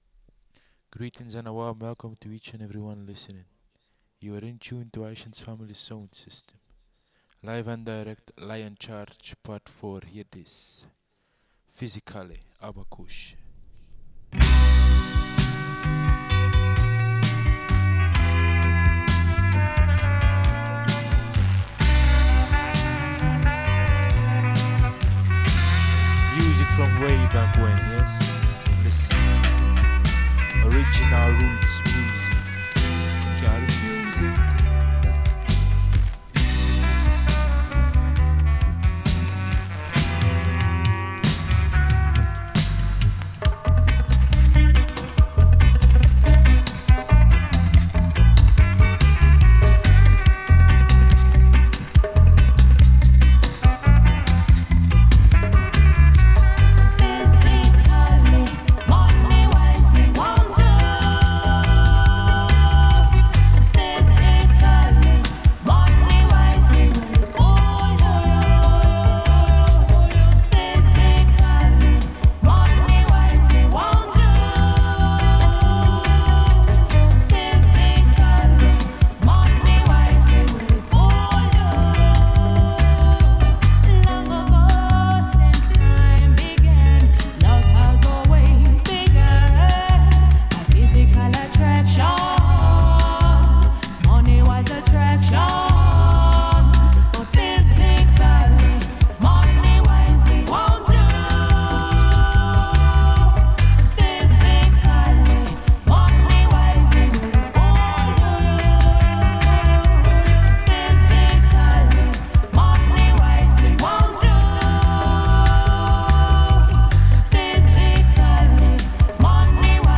From early Roots to deep Dub !